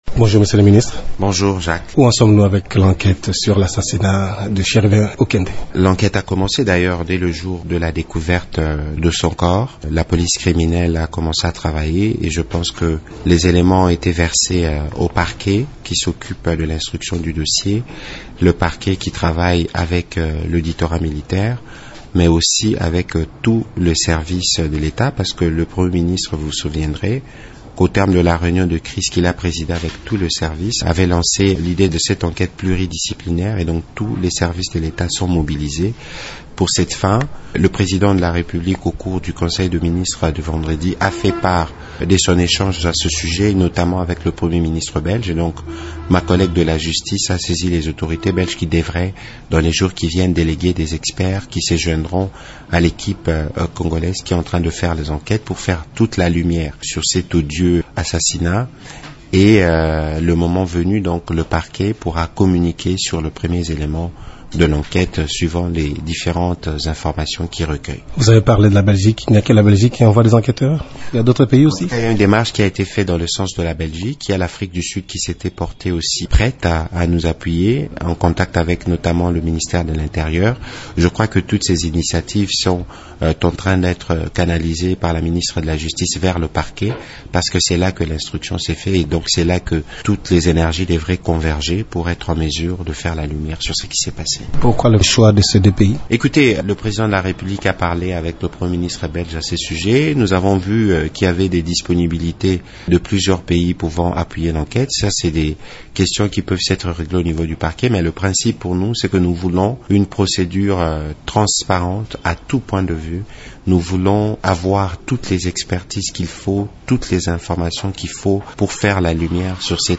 Dans une interview exclusive à Radio Okapi, le porte-parole du Gouvernement a indiqué que « l’enquête a commencé d’ailleurs dès le jour de la découverte de son corps, la police criminelle a commencé à travailler ».